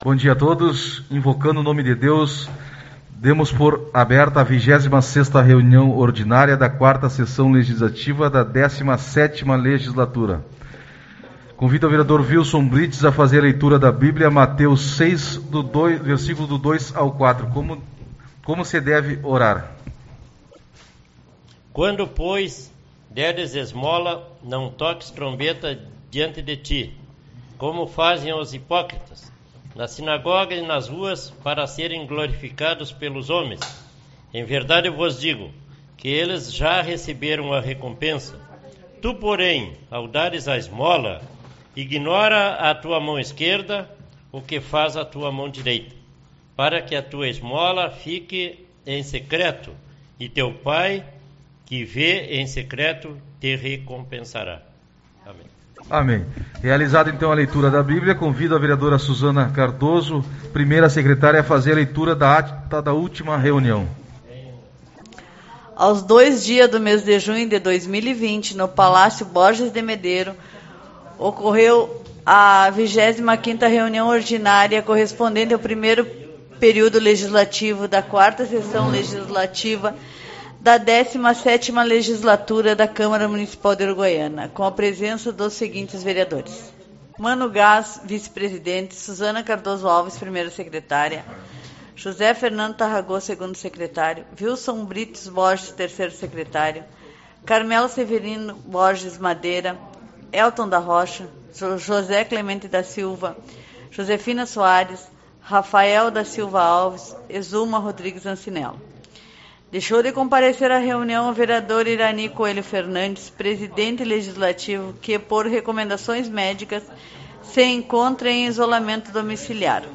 04/06 - Reunião Ordinária